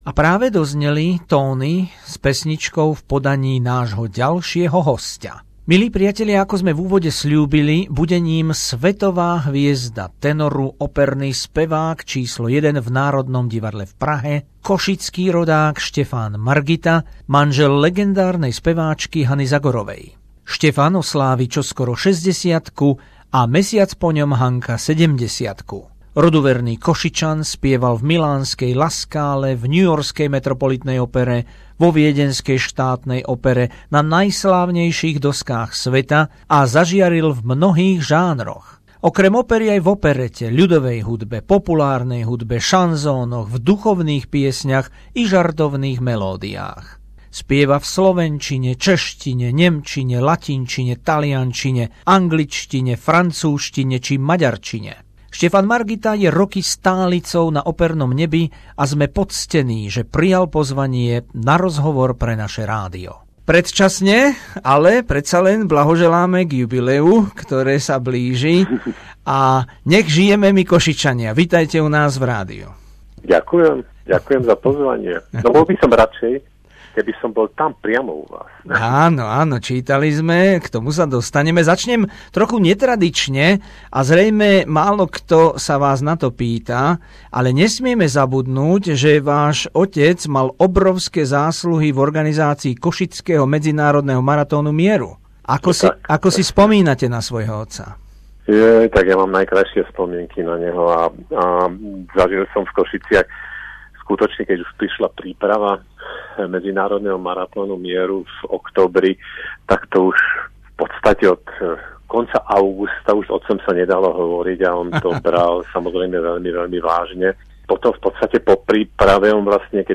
Rozhovor s vynikajúcim slovenským operným spevákom, košickým rodákom Štefanom Margitom, ktorý čoskoro oslávi 60-ku.